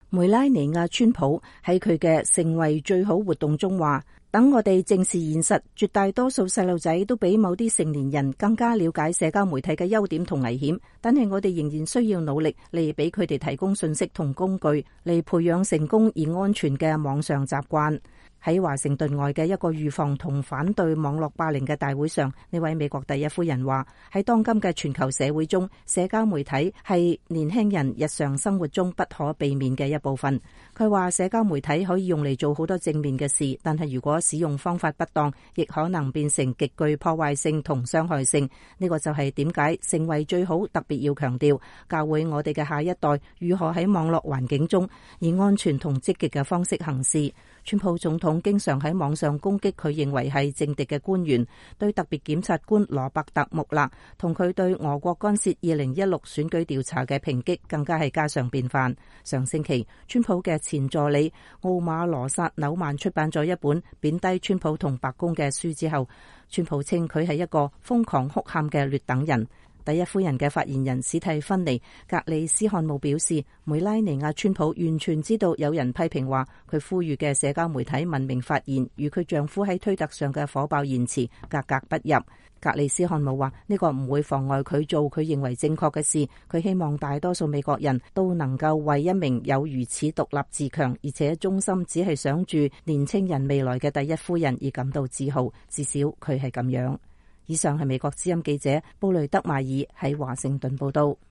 美國第一夫人梅拉妮婭·川普在馬里蘭州洛克維爾參加預防和反對網絡霸凌的峰會。 （2018年8月20日）